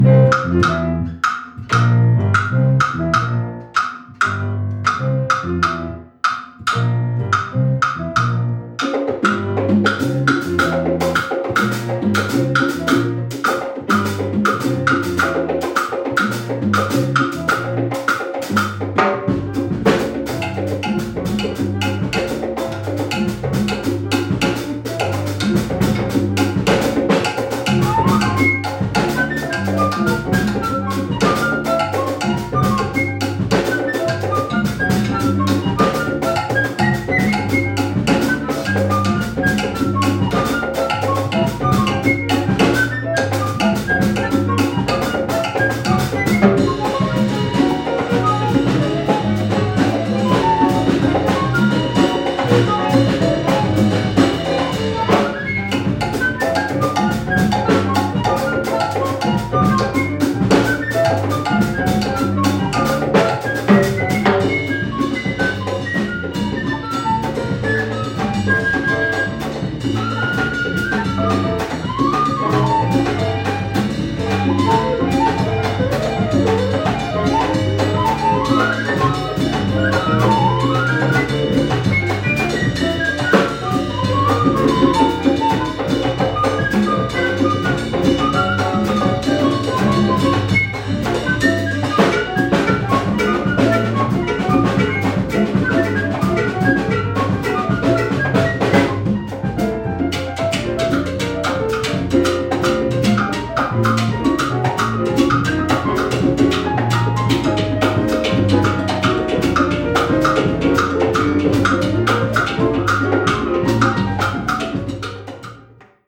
Afro-Cuban jazz
flutes
piano
bass
congas